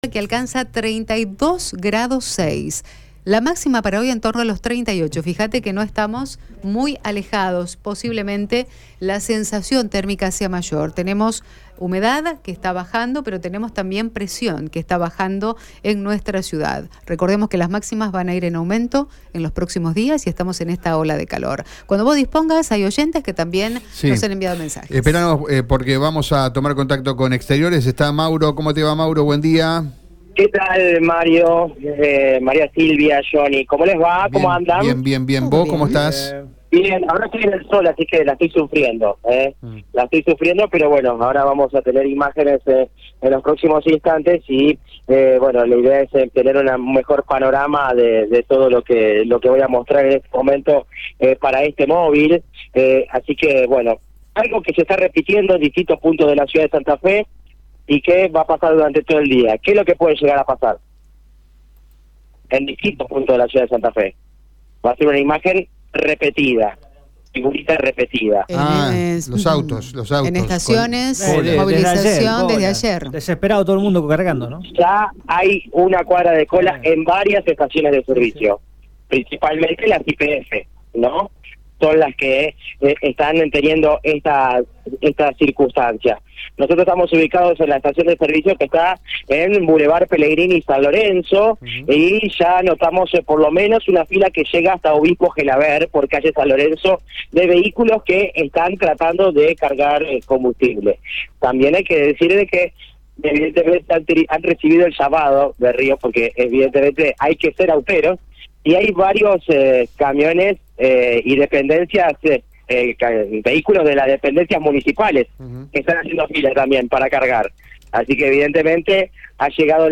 charló con los vecinos de la ciudad de Santa Fe que realizan fila para ahorrarse unos pesos previo al posible aumento.